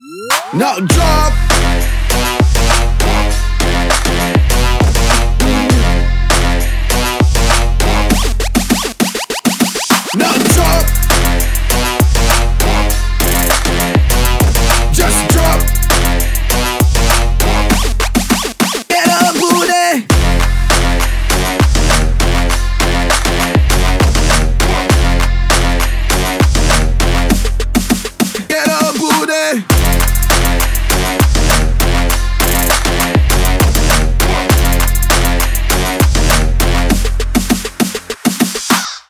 Electronic
мощные басы
Trap
Brazilian bass
качающие
twerk